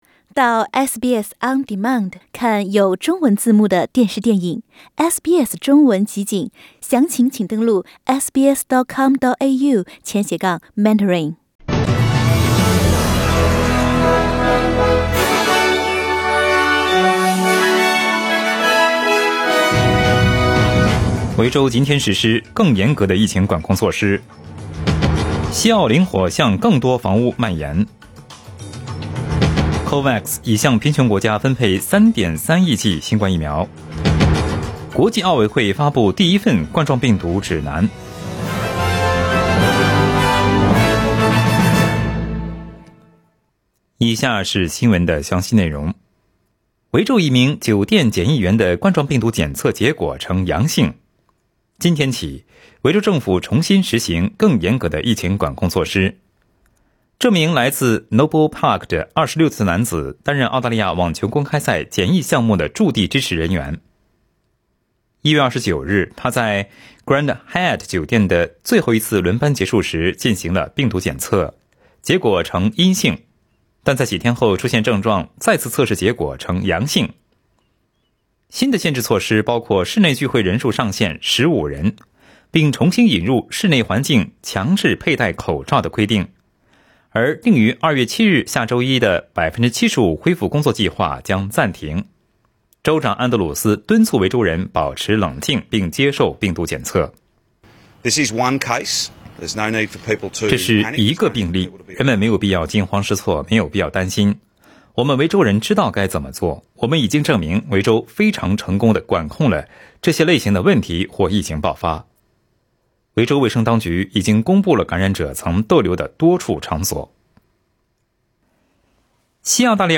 SBS早新闻（02月04日）